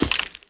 1.21.5 / assets / minecraft / sounds / mob / bogged / step3.ogg
step3.ogg